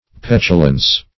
Petulance \Pet"u*lance\, Petulancy \Pet"u*lan*cy\, n. [L.